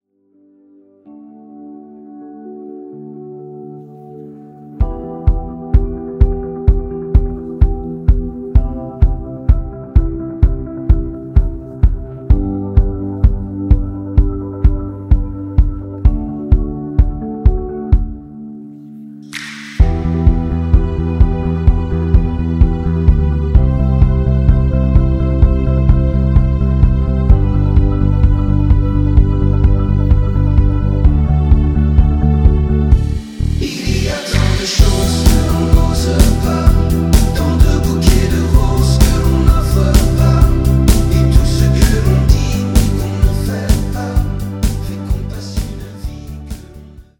(avec choeurs)